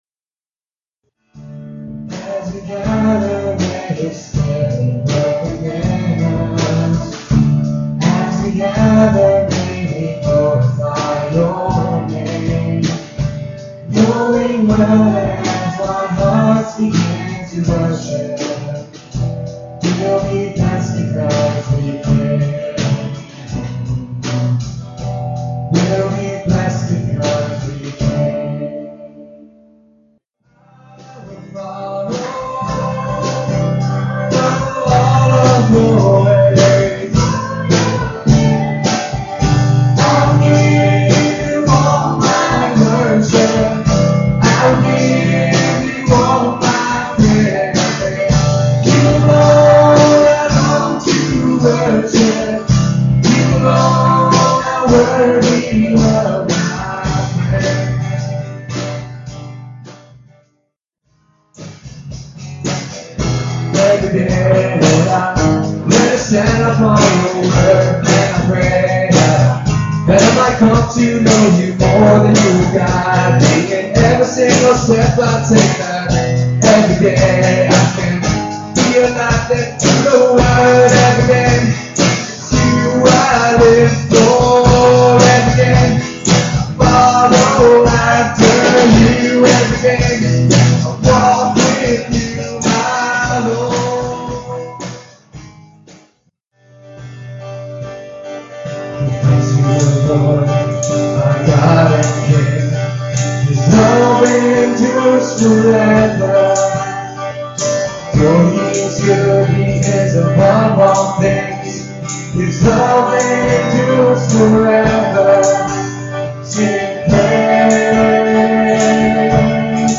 at Ewa Beach Baptist Church. EBBC Worship Team
guitar and vocals
electric bass, vocals